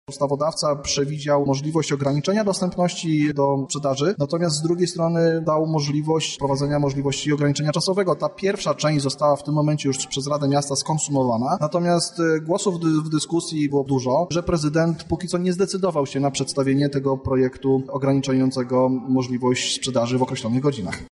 Mówi o tym Piotr Popiel, radny PiS: